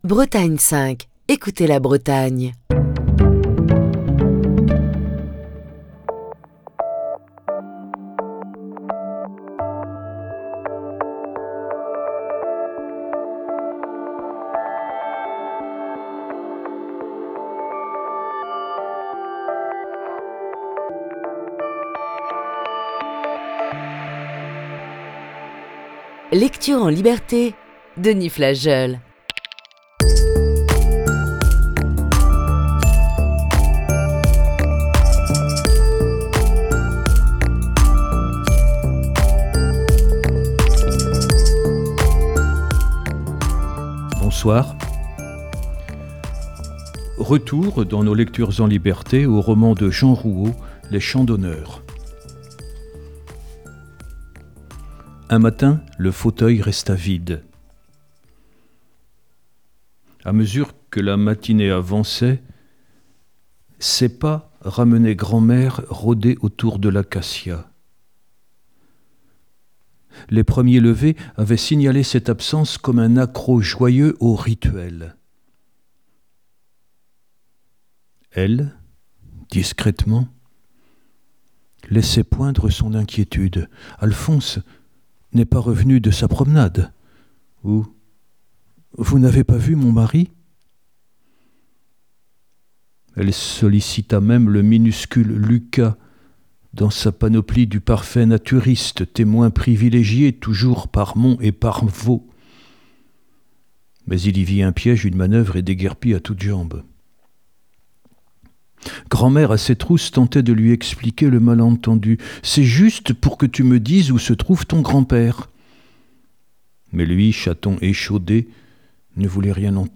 Émission du 21 octobre 2021.